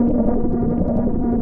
RI_ArpegiFex_170-01.wav